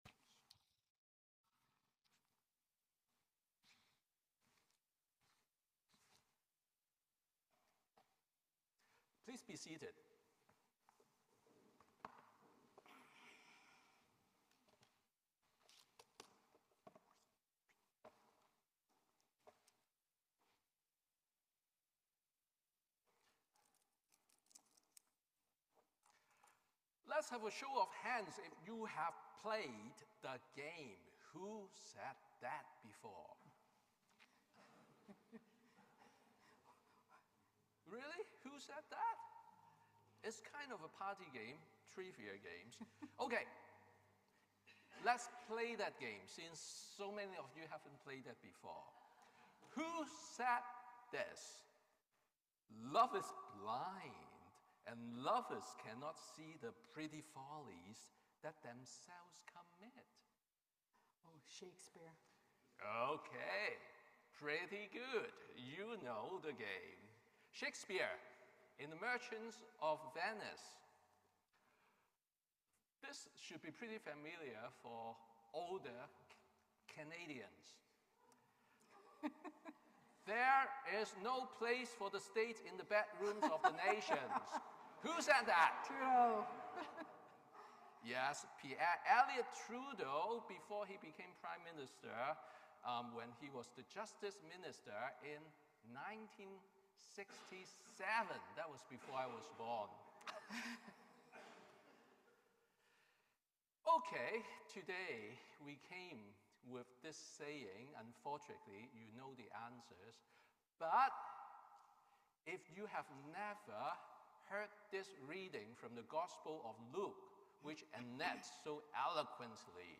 Sermon on the Tenth Sunday after Pentecost